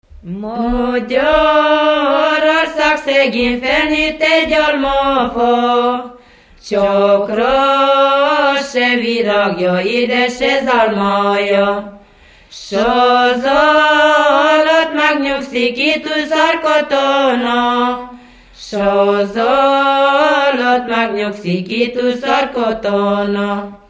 Moldva és Bukovina - Moldva - Külsőrekecsin
Stílus: 7. Régies kisambitusú dallamok
Szótagszám: 6.6.6.6